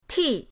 ti4.mp3